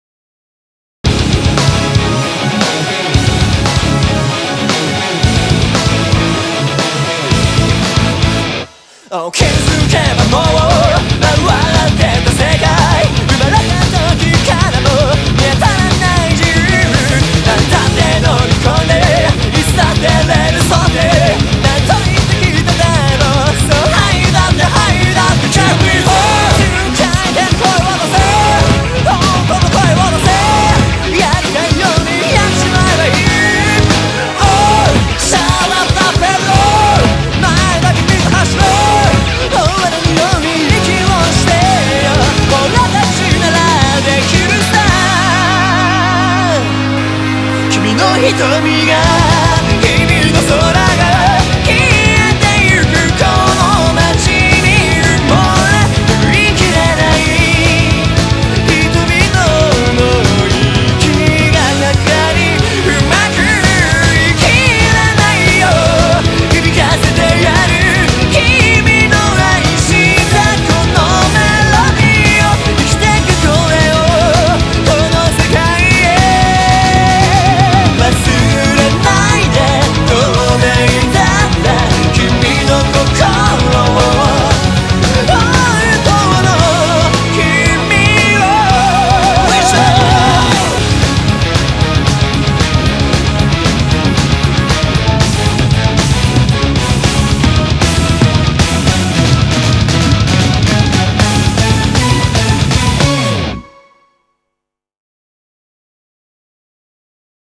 BPM115-230